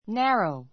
narrow nǽrou ナ ロウ 形容詞 （幅 はば が） 狭 せま い ⦣ 部屋の広さなどが「狭い」というときは small を使う. a narrow street a narrow street 狭い道路 a narrow mind a narrow mind 狭い心 This river is narrow here but is very wide [broad] near its mouth.